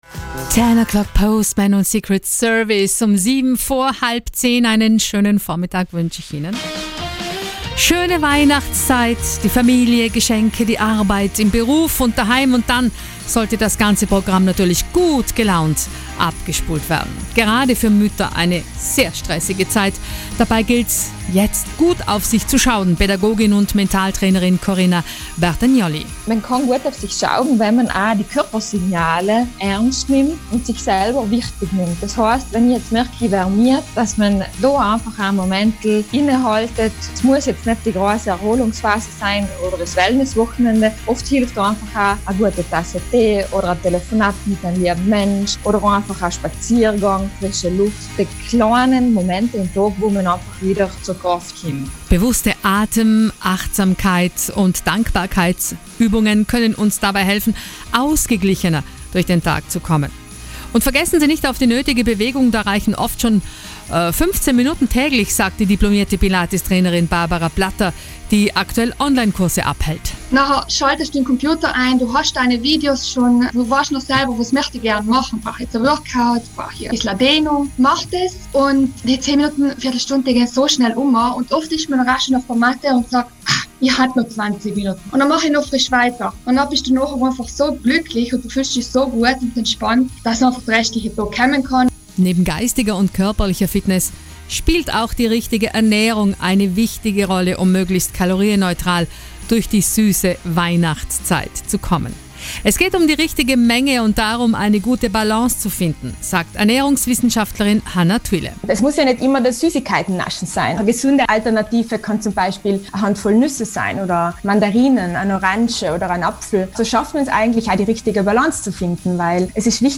Mitschnitt_RT_kalorienneutral_durch_die_Weihnachtszeit.MP3